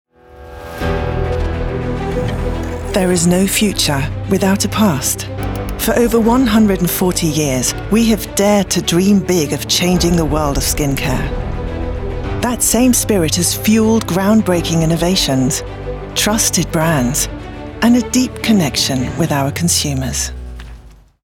sehr variabel, dunkel, sonor, souverän
Mittel plus (35-65)
English - emotive, proud, motivational
Commercial (Werbung), Narrative